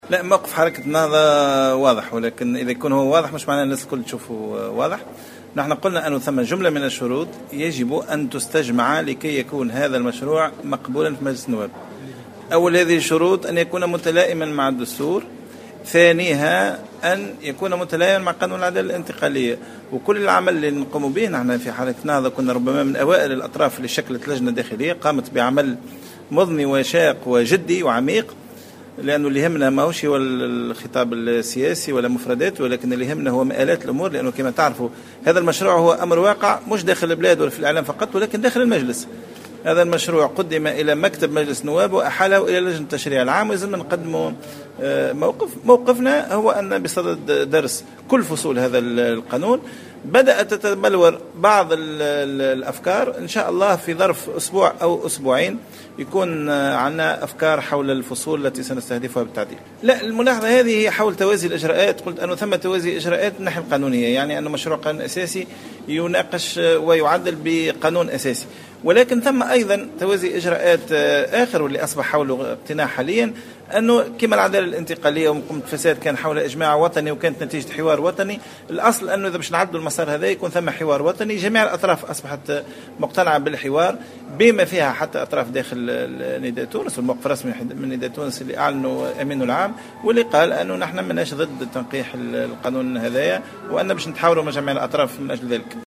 أكد القيادي في حركة النهضة سمير ديلو في تصريح للجوهرة أف أم ان مشروع قانون المصالحة هو امر واقع وسيتم في غضون الاسبوعين القادمين تحديد الفصول التي سيستهدفها التنقيح والتعديل.